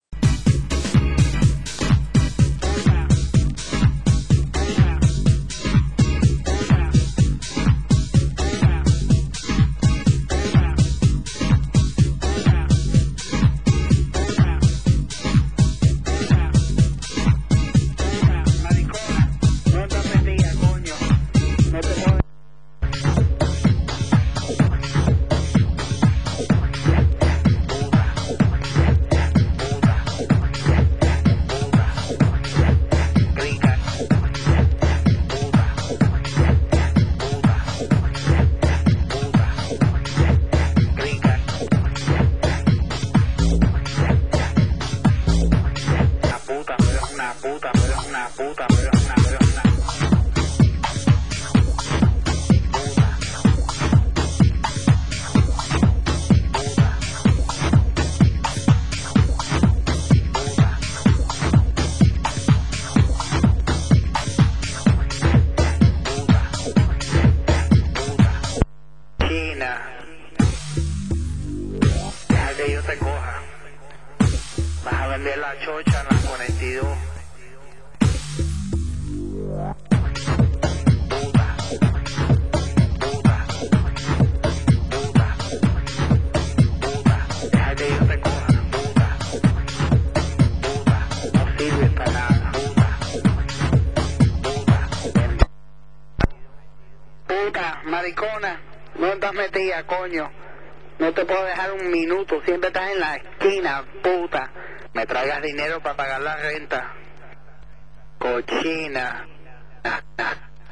Genre: Tech House